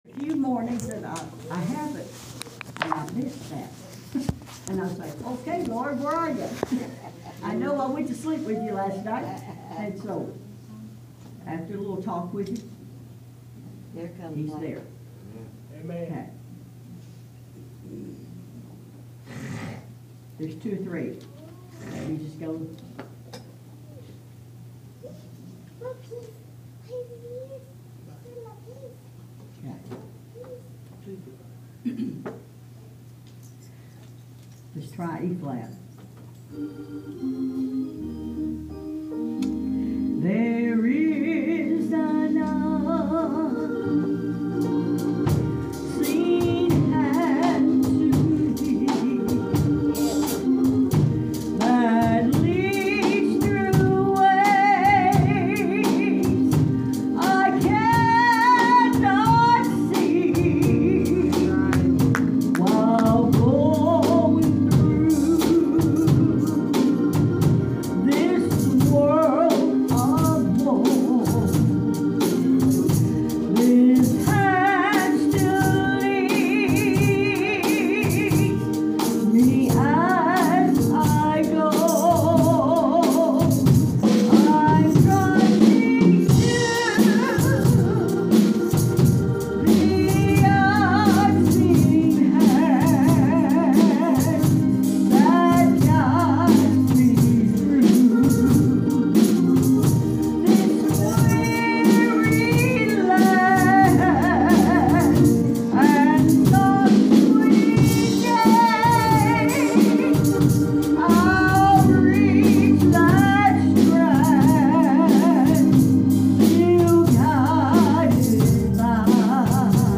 I visited a church last week and wanted you guys to hear his awesome preaching.